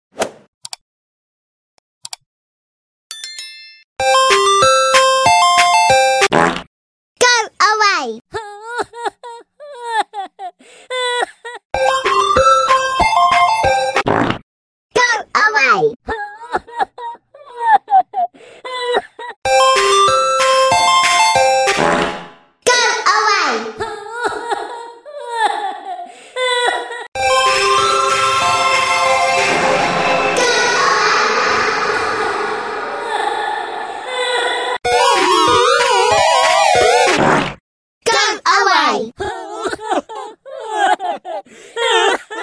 Pocoyo "Fart" & Ice Cream sound effects free download
Pocoyo "Fart" & Ice Cream Truck "Crying" Sound Variations in 43 Seconds